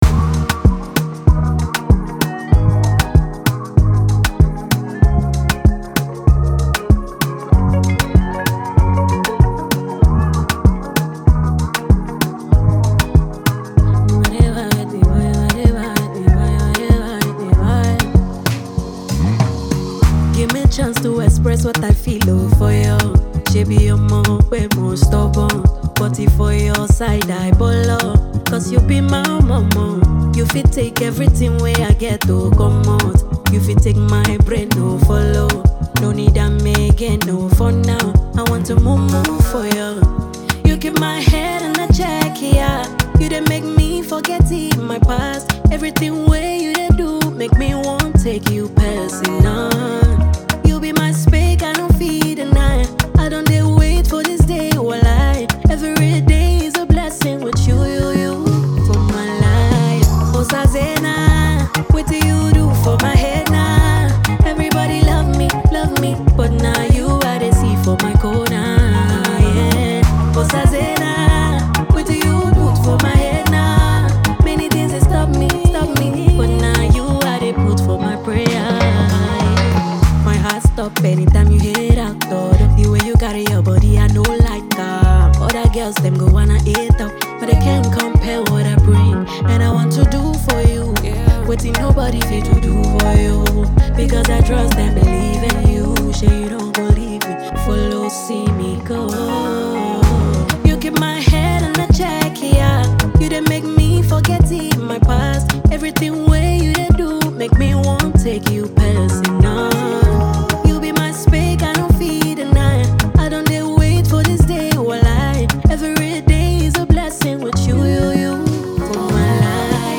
From viral OG-song covers to original Afropop statements